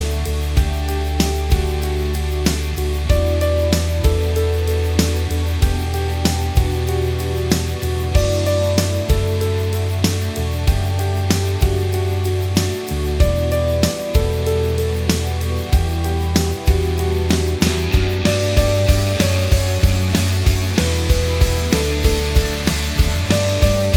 Minus All Guitars Pop (2000s) 4:03 Buy £1.50